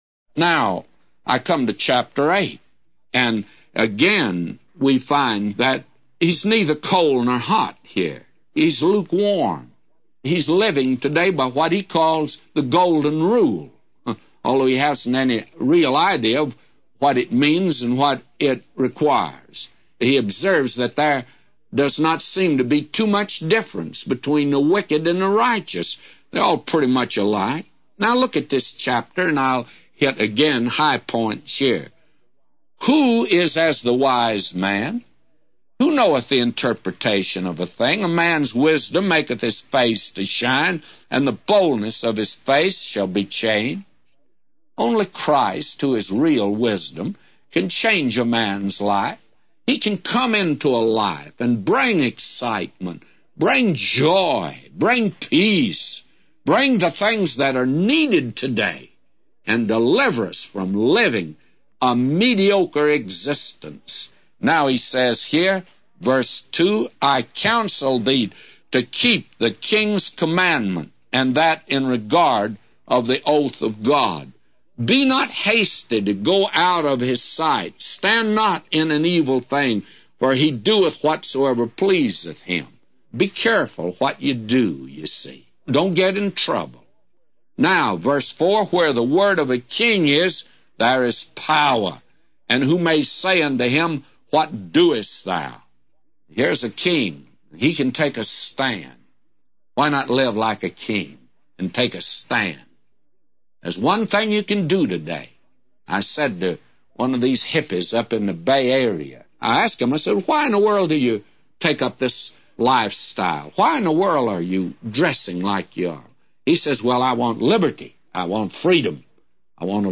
A Commentary By J Vernon MCgee For Ecclesiastes 8:1-999